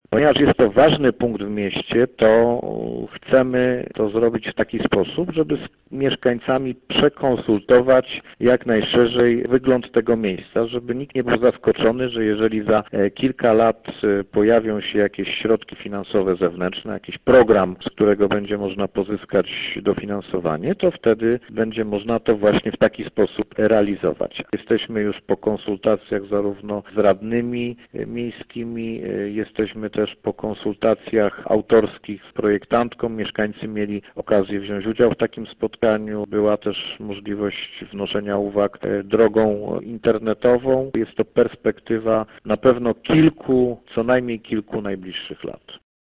– Plany rewitalizacji to kwestia najbliższych lat. Dlatego jak najlepiej chcemy się do tego przygotować – dodaje burmistrz Piwowarczyk.